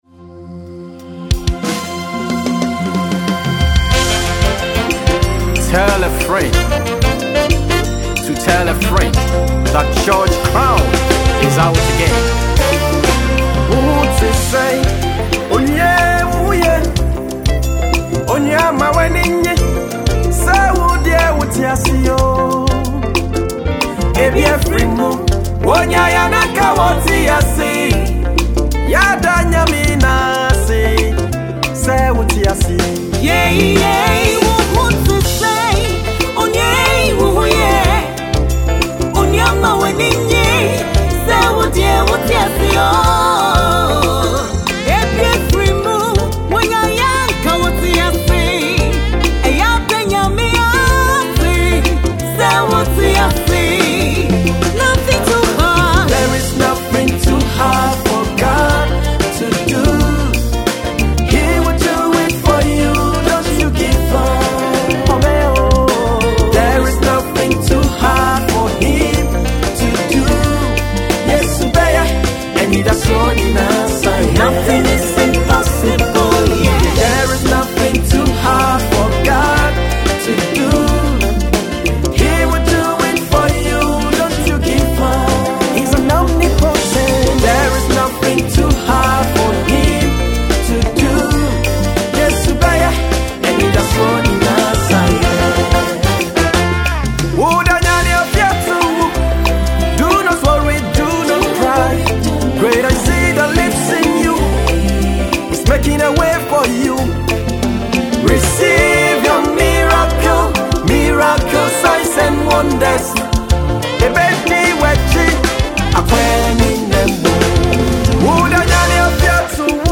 Gospel singer
a beautiful inspirational highlife piece
another dynamic singer.